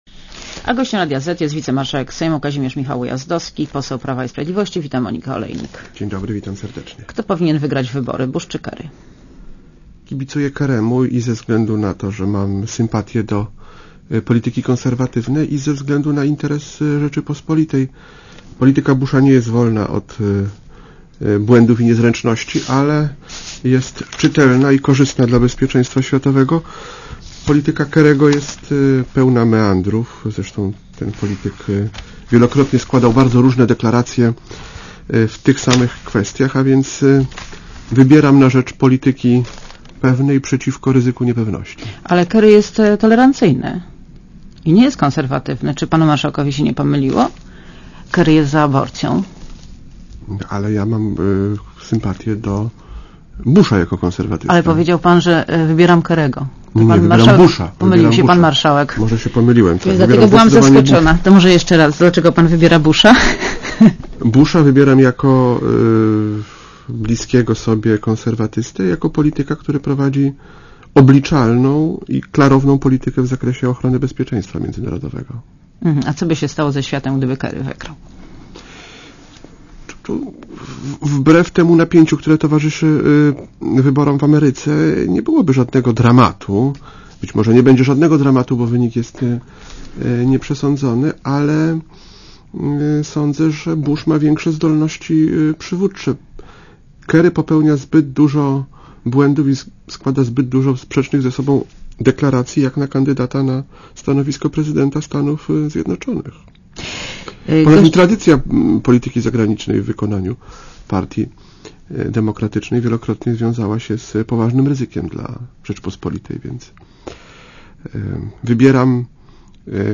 Posłuchaj wywiadu Gościem Radia Zet jest wicemarszałek Sejmu, Kazimierz Michał Ujazdowski, poseł PiS – u. Witam, Monika Olejnik.